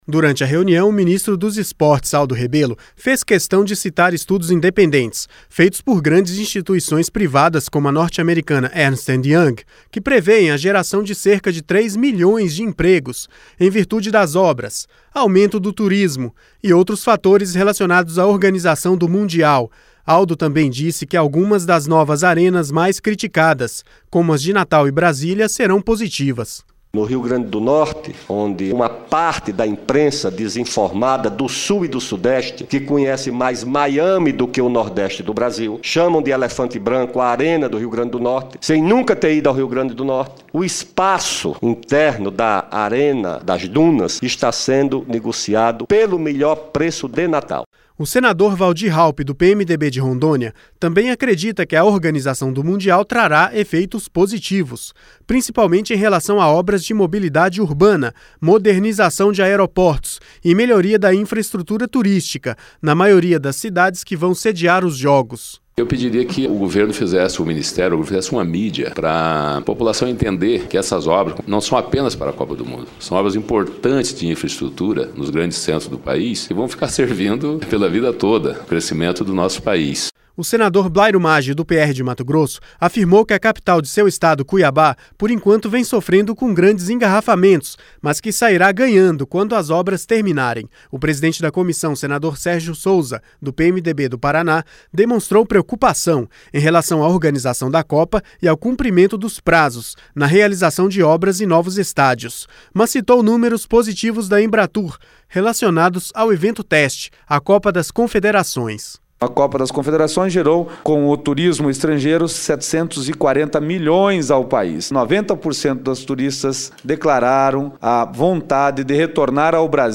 LOC: A COPA DO MUNDO EM 2014 VAI SER UMA BOA OPORTUNIDADE PARA A GERAÇÃO DE EMPREGOS E DE MELHORIA DA INFRAESTRUTURA PARA O PAÍS. LOC: A AVALIAÇÃO FOI FEITA NESTA TERÇA-FEIRA DURANTE AUDIÊNCIA PÚBLICA COM O MINISTRO DO ESPORTE, ALDO REBELO, NA SUBCOMISSÃO DO SENADO QUE ACOMPANHA A ORGANIZAÇÃO DO MUNDIAL DE FUTEBOL.